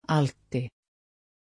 Aussprache von Altti
pronunciation-altti-sv.mp3